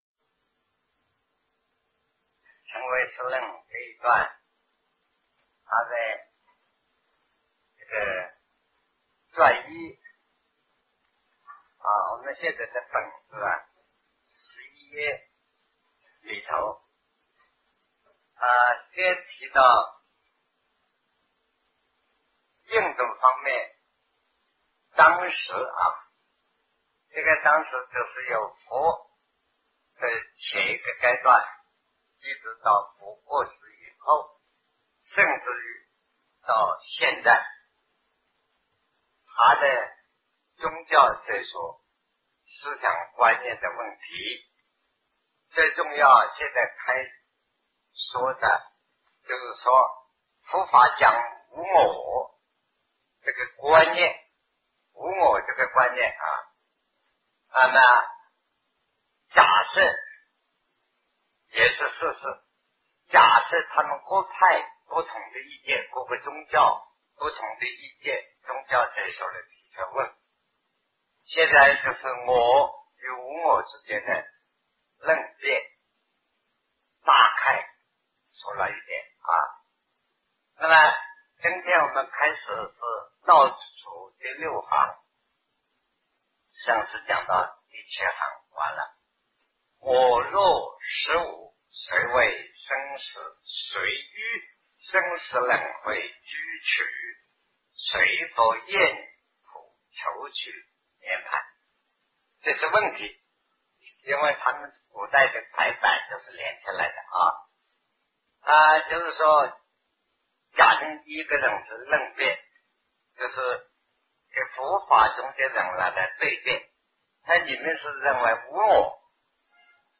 南因果相续 师讲唯识与中观（1980代初于台湾055(上)